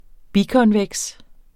Udtale [ ˈbikʌnˌvεgs ]